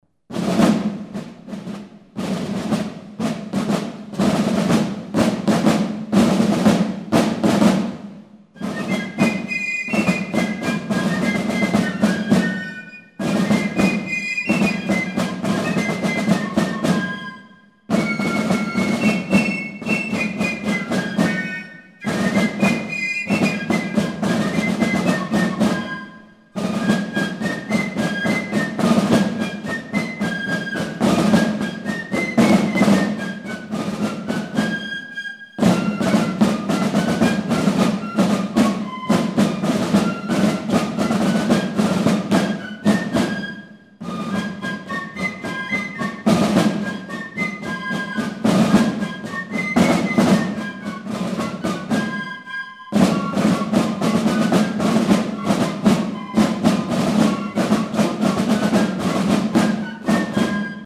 Notre fifre, muni de six clefs, permet de jouer tous les demi-tons sur une tessiture de deux octaves et demie.
Le tambour que nous utilisons est du type junior bĂ˘lois.
Un timbre mĂ©tallique ainsi qu'un timbre en corde permettent d'effectuer le rĂ©glage de la sonoritĂ©.
Notre rĂ©pertoire, entiĂ¨rement appris par cœur, se compose d'airs traditionnels ou militaires franĂ§ais, amĂ©ricains et suisses, y compris des morceaux du Carnaval de BĂ˘le, dont vous connaissez la rĂ©putation.